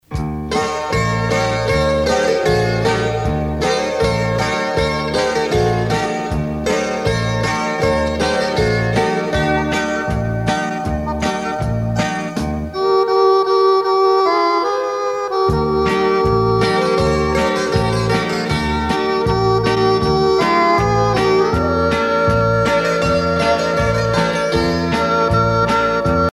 danse : sirtaki
Pièce musicale éditée